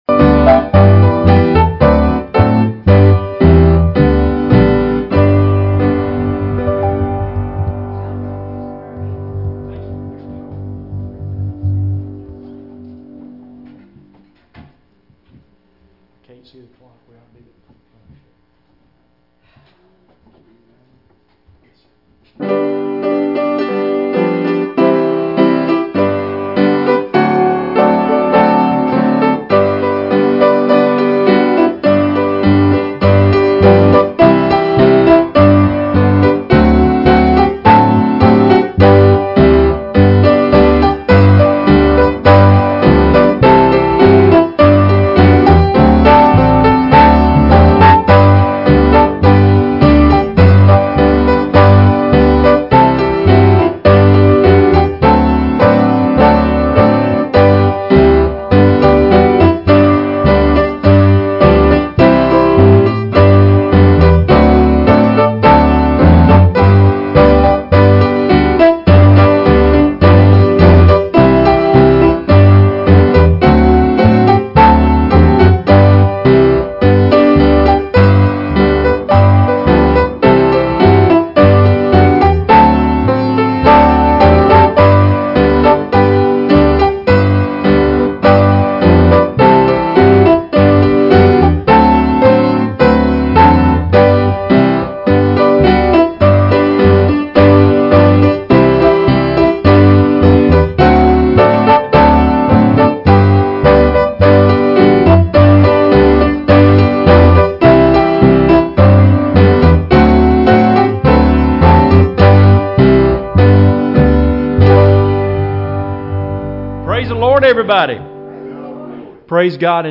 Passage: 1 Peter 1:3-5 Service Type: Wednesday Evening Services Topics